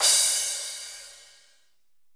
LITE CRSH.wav